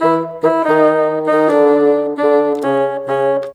Rock-Pop 01 Bassoon 03.wav